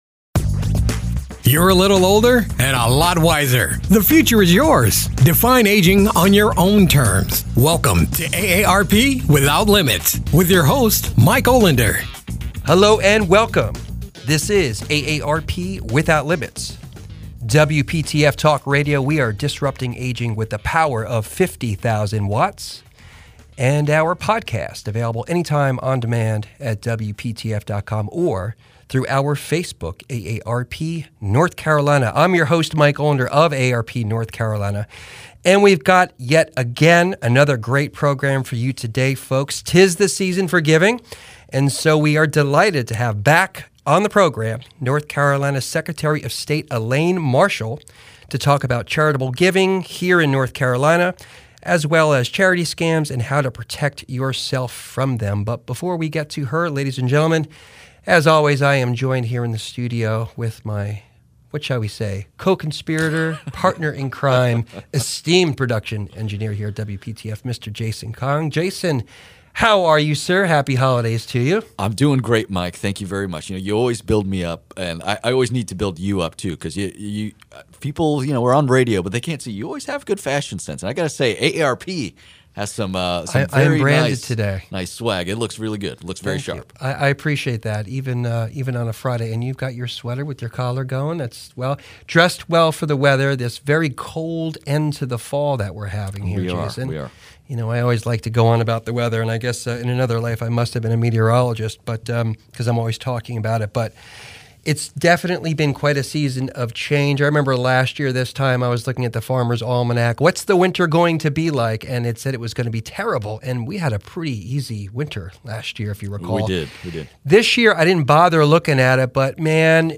Charity fraud is common after disasters and during the holiday season. But how common is fraud in NC, and how can you tell if a charity is legit? NC Secretary of State Elaine Marshall returns to the show to explain it all.